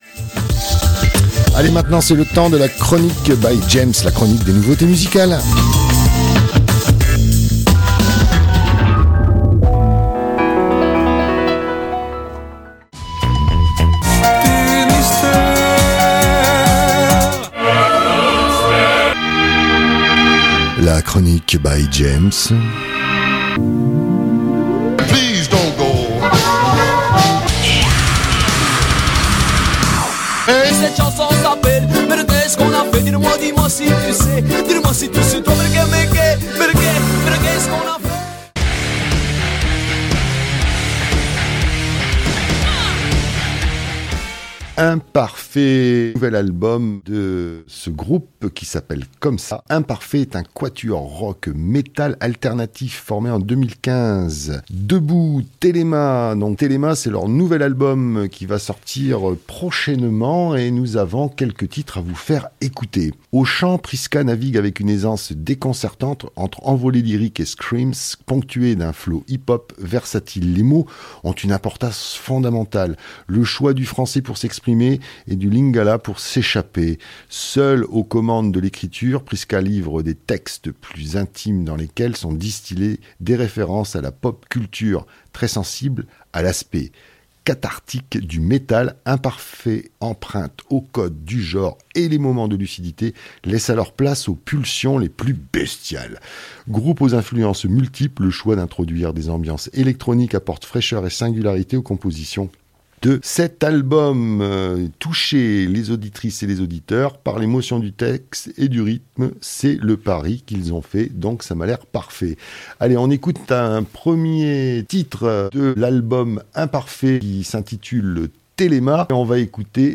IMPARFAIT est un quatuor rock / métal alternatif formé en 2015.